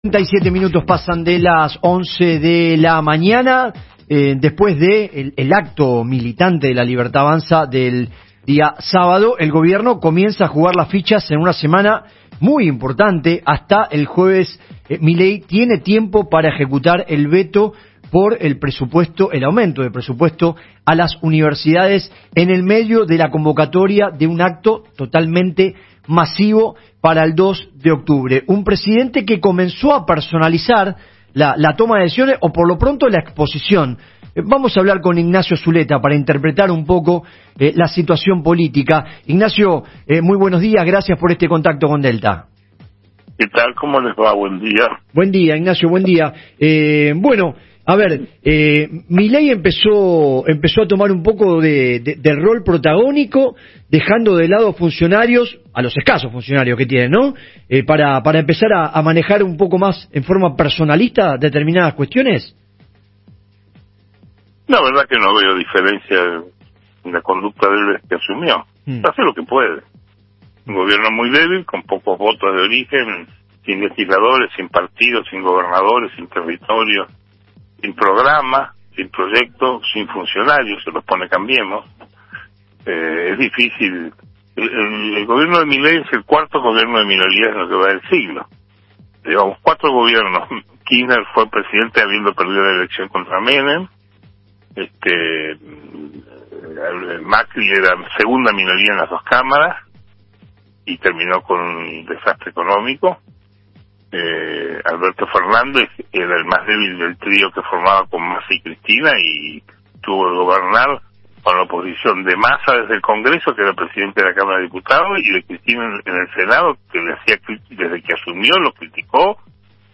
Comparto un diálogo con colegas de radio Delta FM 90.3. Conversamos sobre la capacidad de construcción de fuerza que tiene el gobierno para asegurarse la gobernabilidad y superar la debilidad de origen.